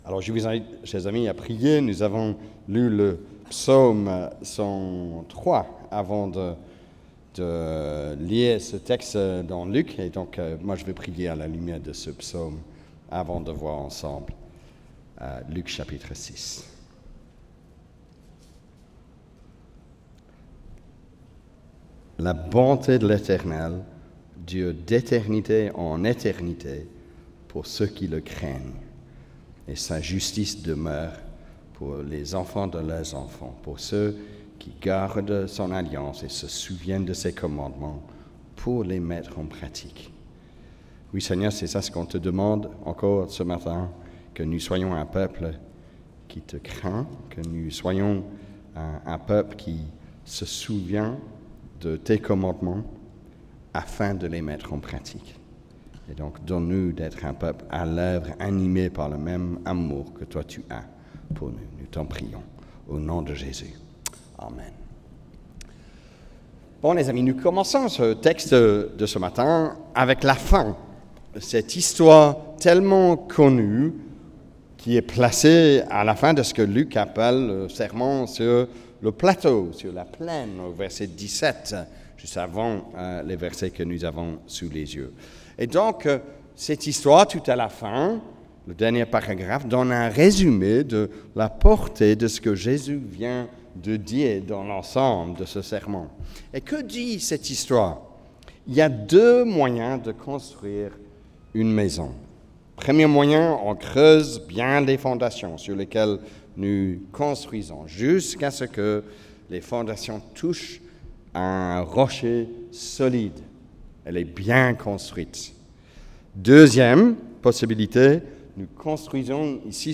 Nous nous excusons que l’enregistrement de cette prédication n’est pas complète: uniquement les premières 20 minutes ont été enregistré.